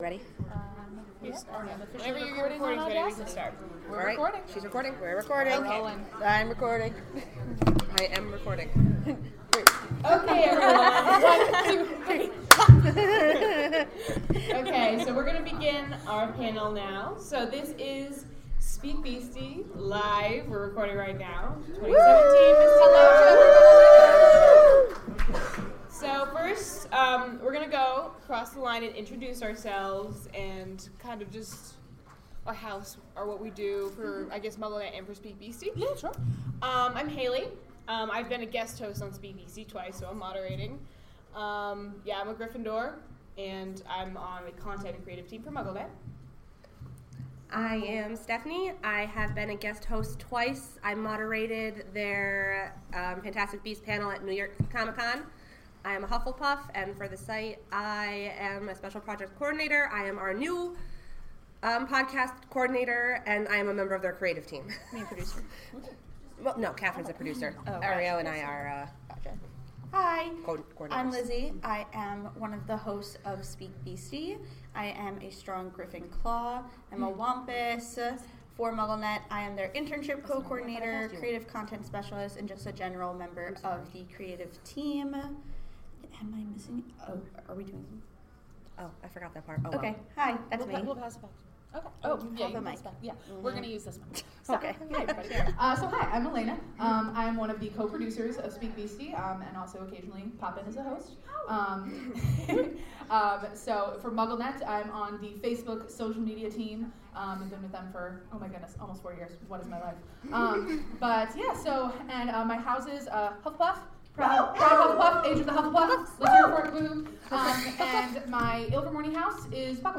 This time on SpeakBeasty: → We're LIVE at MISTI-Con! → What would Newt's boggart be?